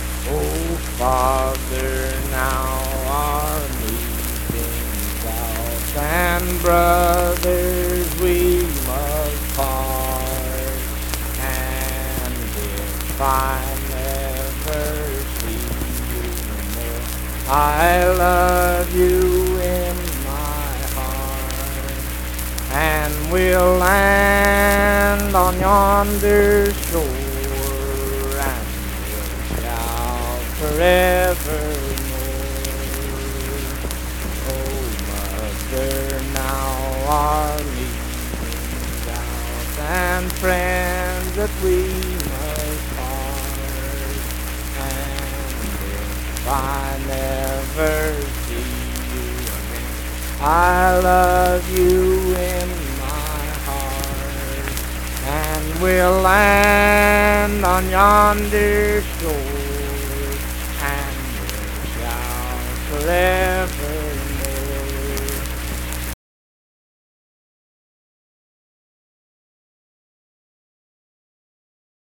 Unaccompanied vocal music
Hymns and Spiritual Music
Voice (sung)
Pleasants County (W. Va.), Saint Marys (W. Va.)